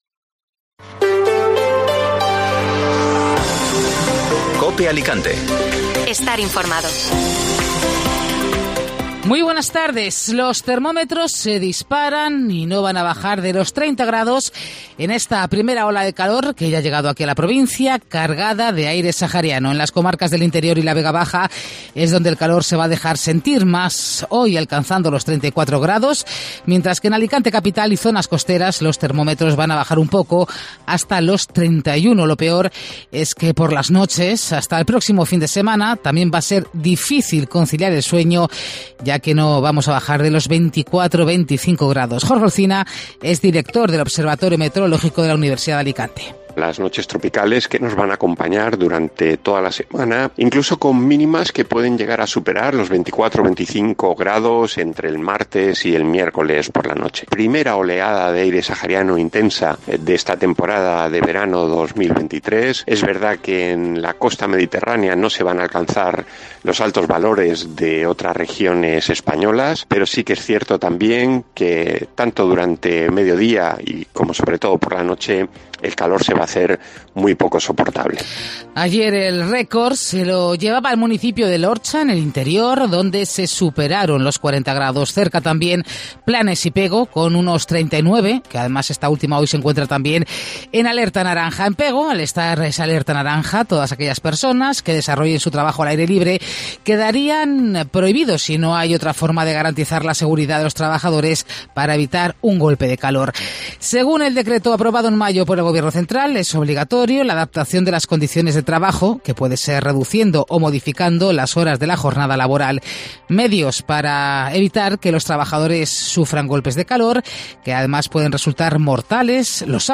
Informativo Mediodía Cope Alicante ( Lunes 26 de junio)